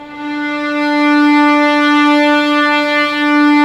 Index of /90_sSampleCDs/Roland - String Master Series/STR_Vlns 6 p-mf/STR_Vls6 mf slo